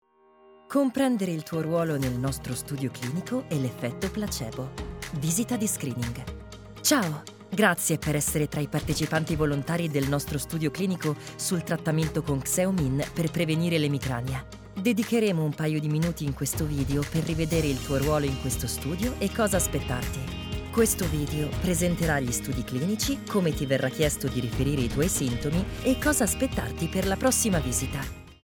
Commercieel, Stoer, Veelzijdig, Vriendelijk, Warm
Explainer